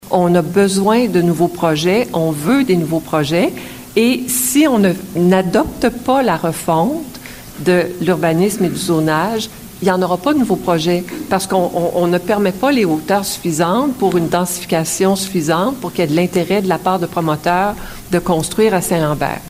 La mairesse Pascale Mongrain rappelle que le but des consultations est de définir à terme l’allure globale de la ville pour les années à venir.